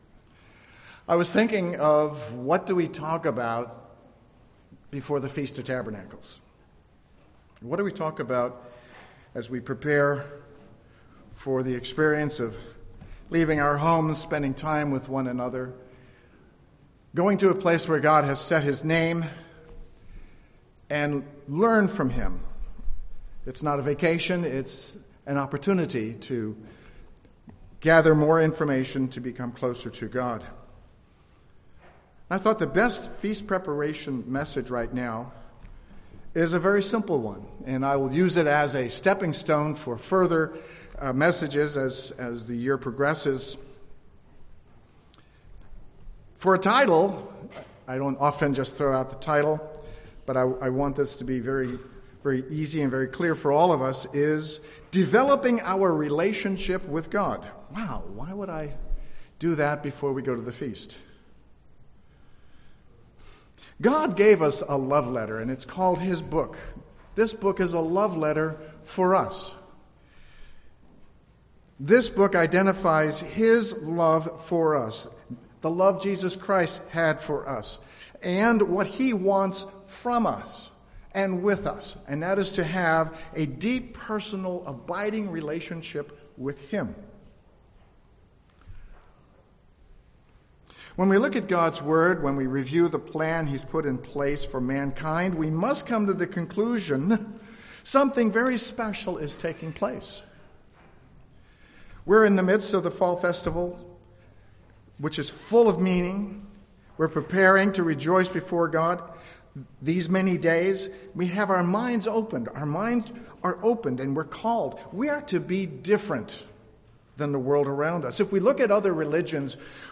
Sermons
Given in San Jose, CA